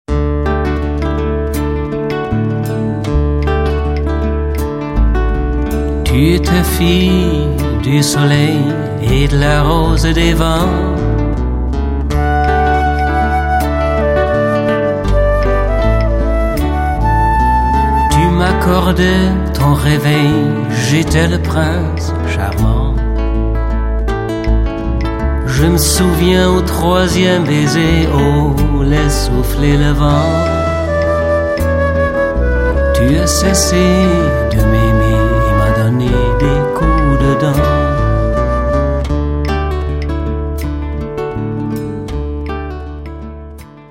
Musik und Gesang